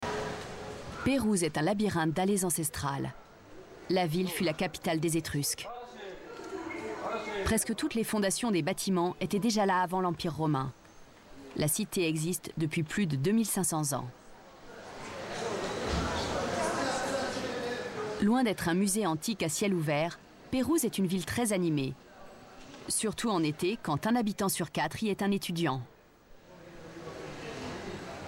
Narration : L'Ombrie